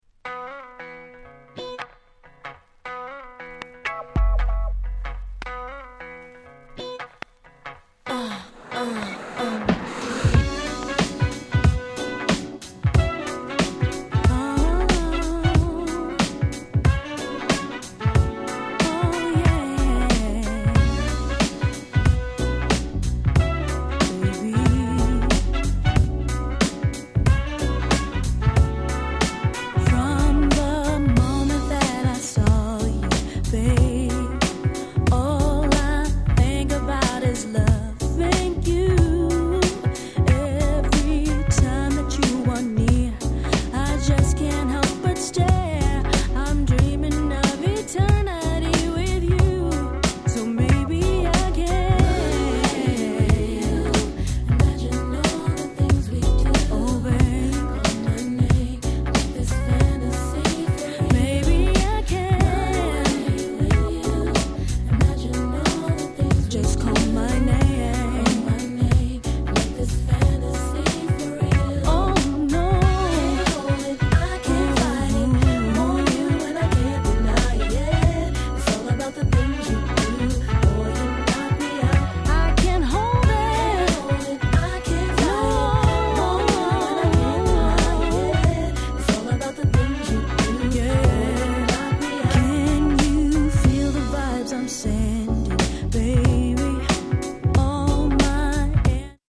恐らくこのネタをこれほどキャッチーに使ってしまった楽曲は他に無いでしょう。